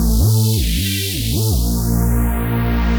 Index of /musicradar/future-rave-samples/160bpm